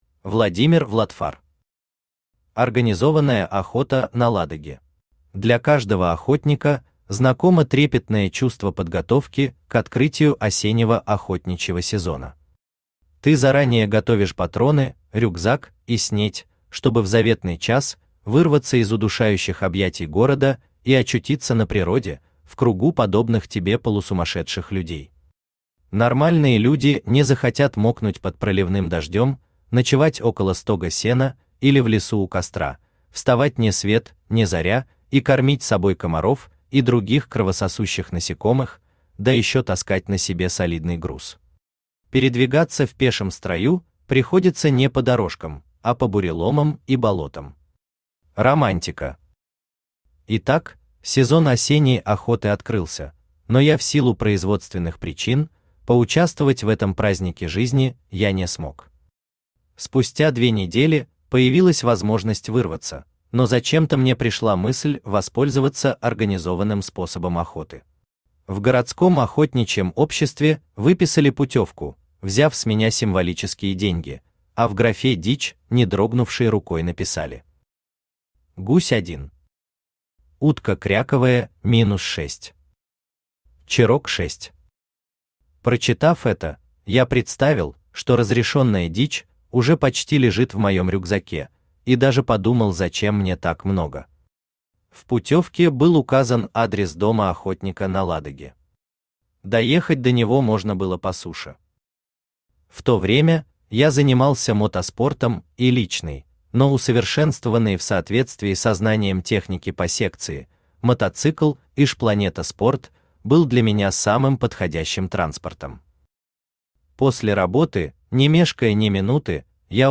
Аудиокнига Организованная охота на Ладоге | Библиотека аудиокниг
Читает аудиокнигу Авточтец ЛитРес